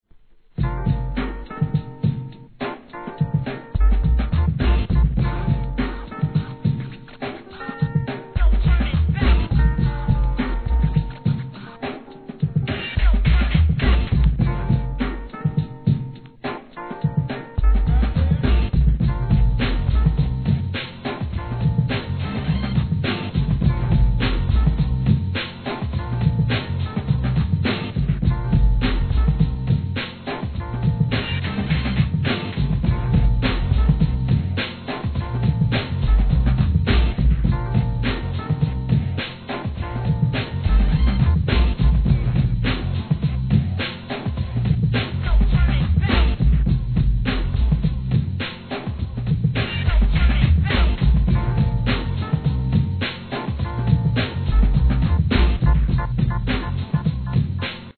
HIP HOP/R&B
ブレイク・ビーツ